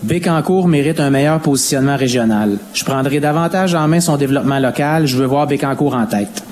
Quelques flèches ont été lancées l’un envers l’autre lors du débat entre les candidats à la mairie de Bécancour qui se tenait mercredi en soirée.
À la fin du débat, les deux candidats se sont adressés aux életeurs.